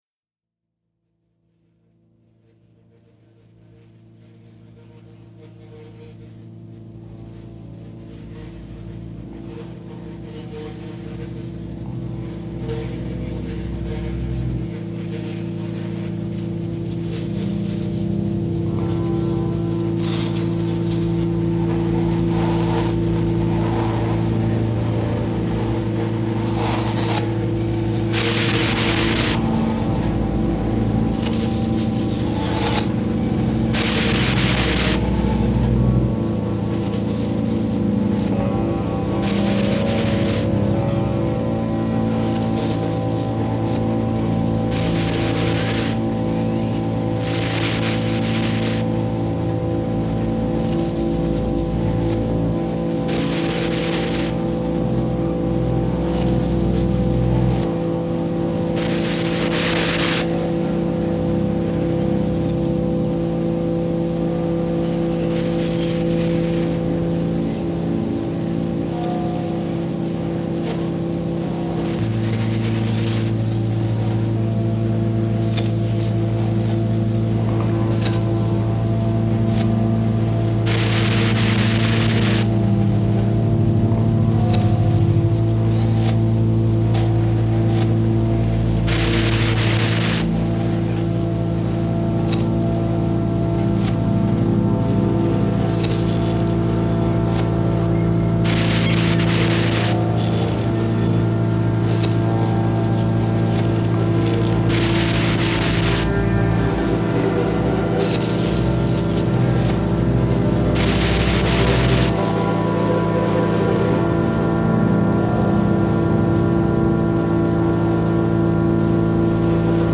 Estonian drones for electric guitar, modular synth, voice and bell
Alexander Nevsky Cathedral, Tallinn